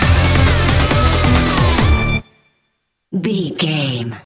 Aeolian/Minor
drum machine
synthesiser
Sports Rock
hard rock
angry
lead guitar
bass
drums
aggressive
energetic
intense
nu metal
alternative metal